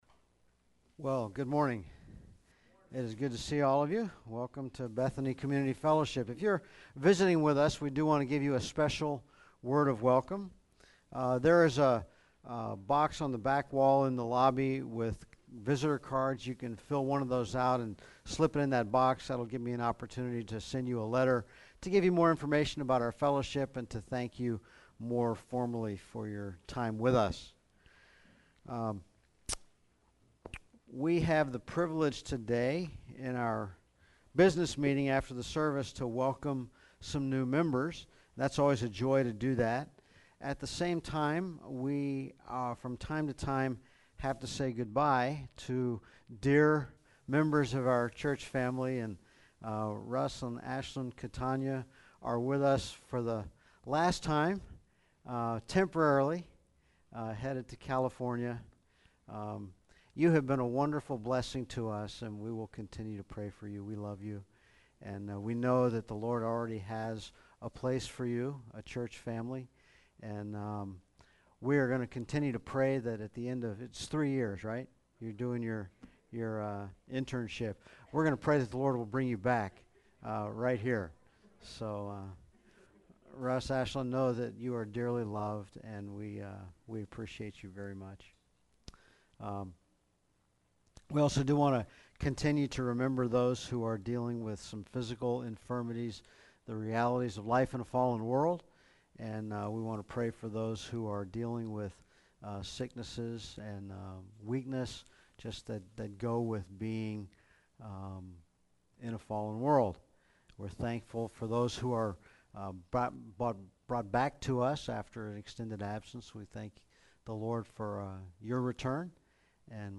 A sermon on the nature, author, and means of sanctification.